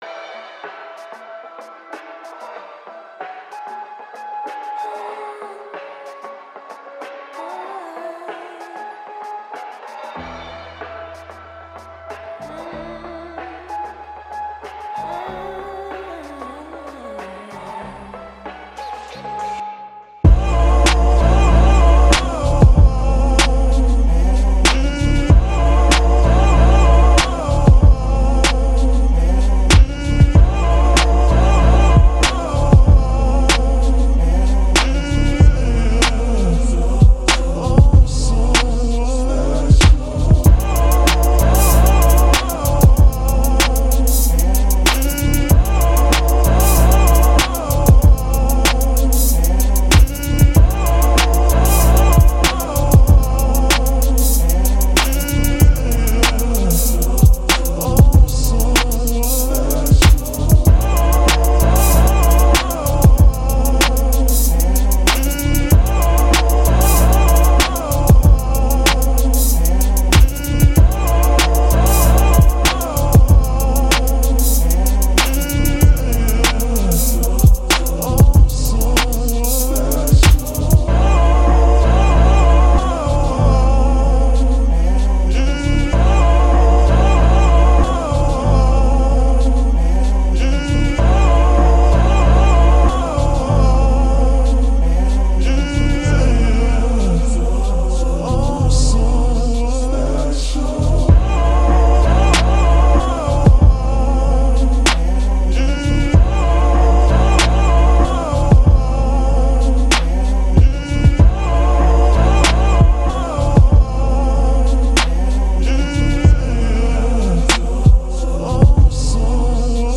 95 BPM.
Music / Rap
90s rhythm and blues beat chill soulful instrumental sample garageband lofi hip hop remix flip jazzy chops old school girl group soul